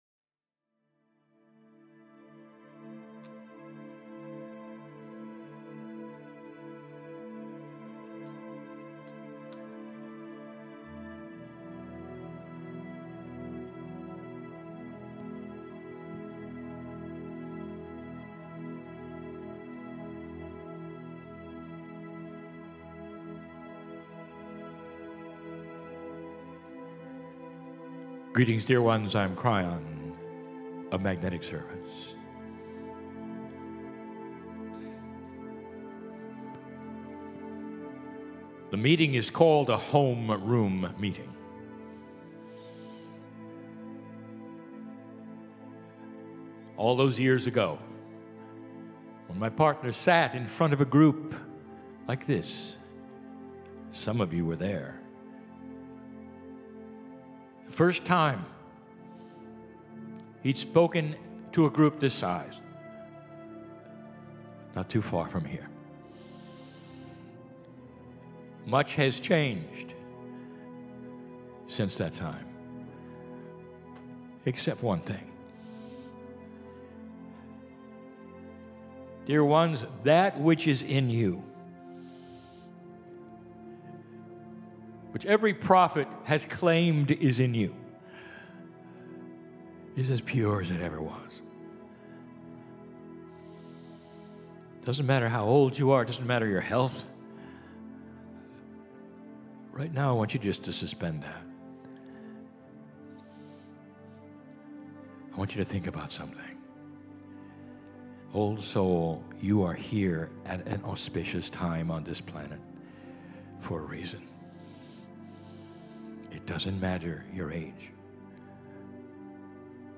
Live Kryon Channelling